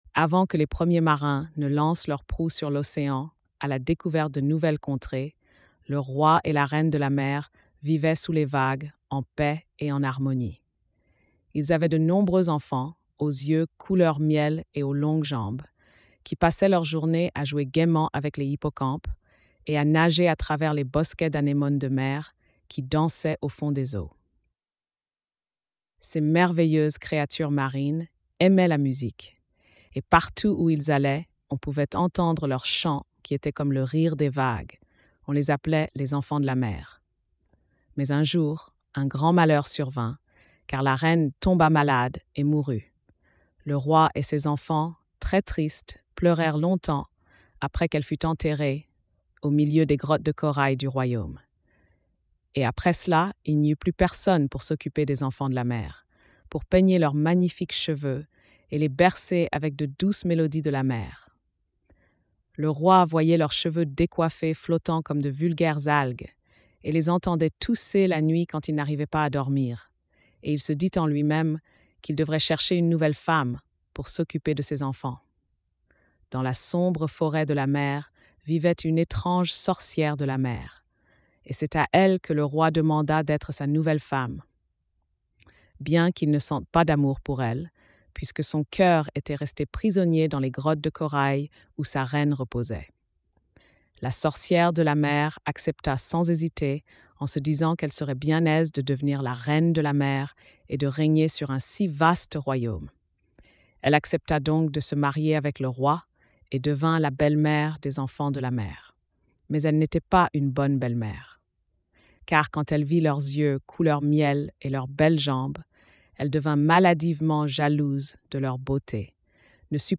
Lecture audio générée par IA